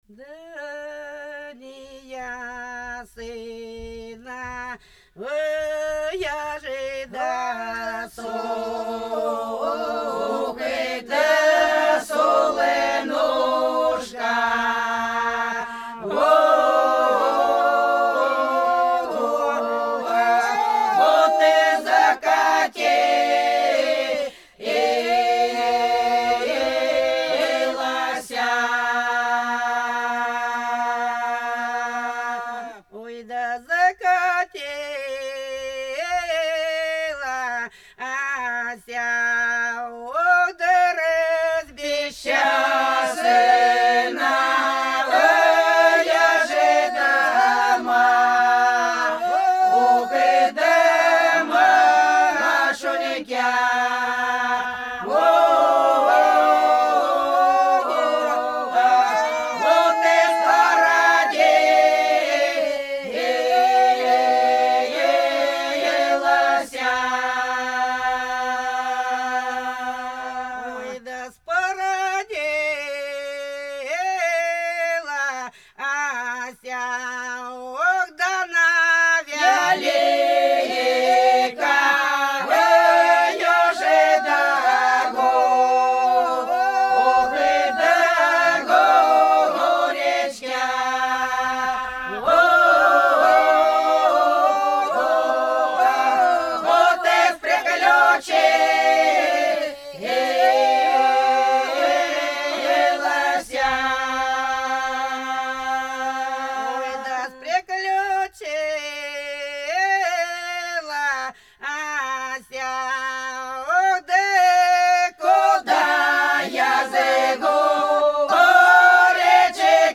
Пролетели все наши года Да не ясное солнышко закаталося – протяжная (Фольклорный ансамбль села Иловка Белгородской области)
06_Да_не_ясное_солнышко_закаталося_–_протяжная.mp3